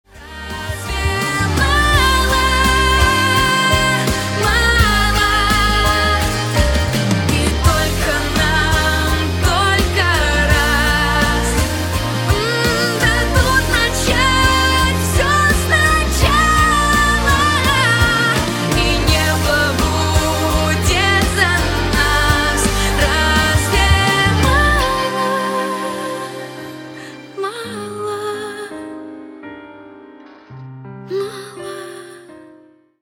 • Качество: 192, Stereo
поп
мелодичные
нежные